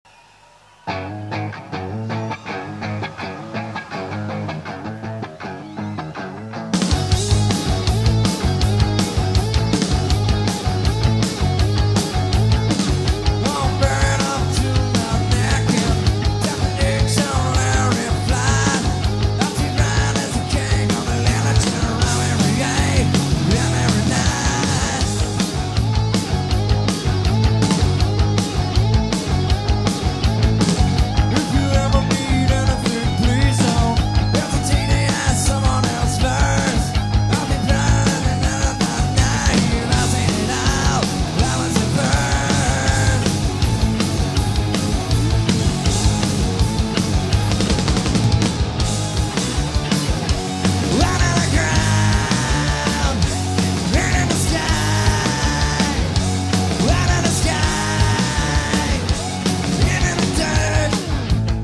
Les meilleurs riffs
Un morceau court, mais puissant et très colérique.
02/22/94 - Palaghiaccio, Roma, IT